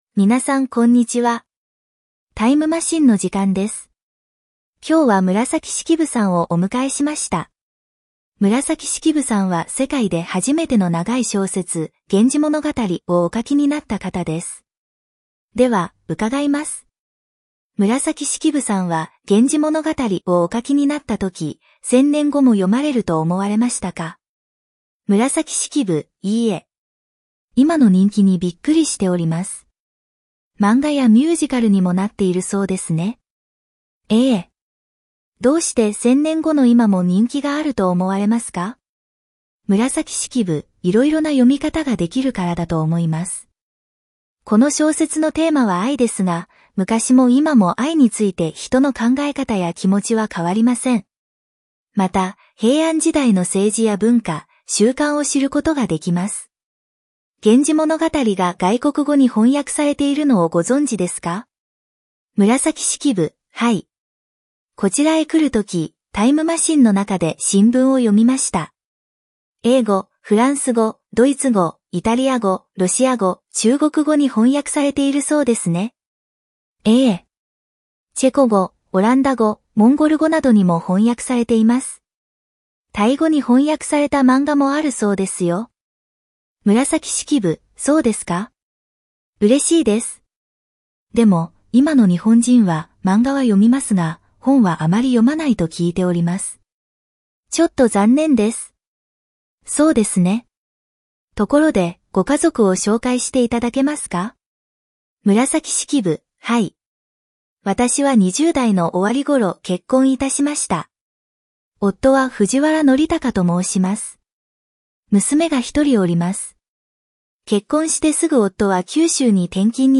インタビューの内容に合っているものに〇、合っていないものに✖を書いてください。